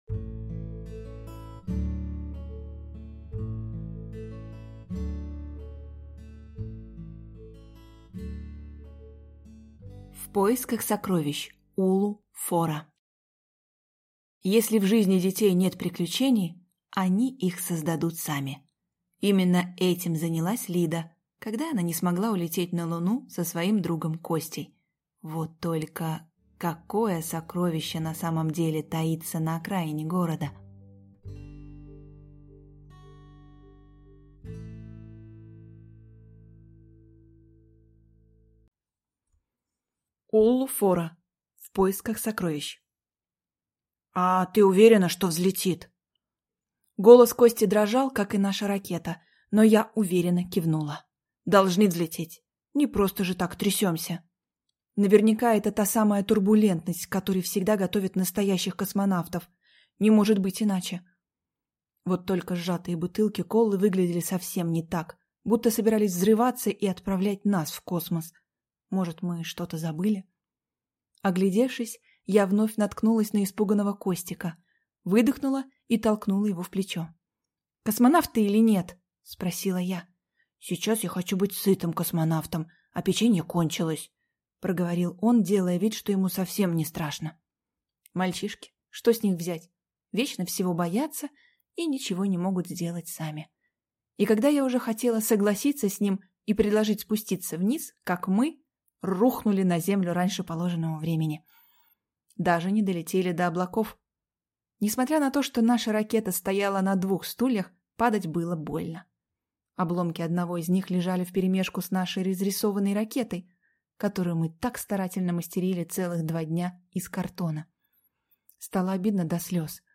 Аудиокнига В поисках сокровищ | Библиотека аудиокниг